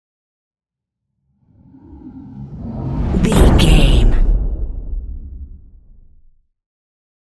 Cinematic whoosh to hit deep
Sound Effects
Atonal
dark
intense
tension
woosh to hit